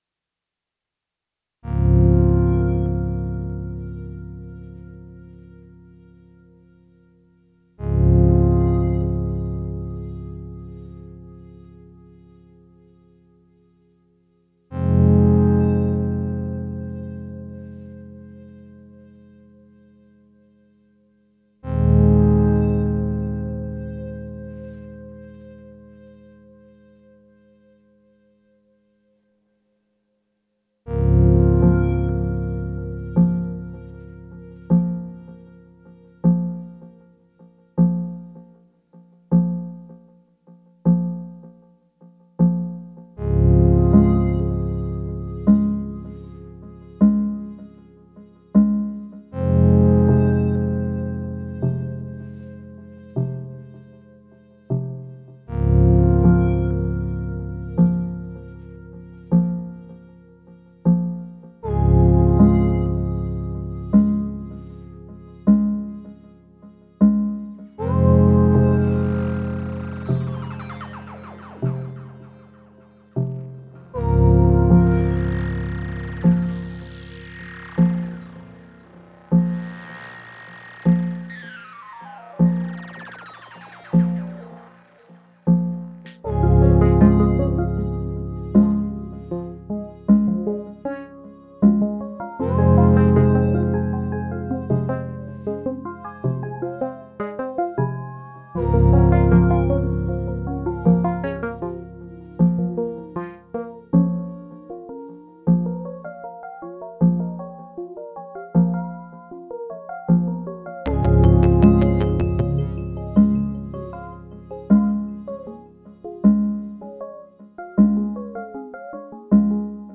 layering syntakt with digitone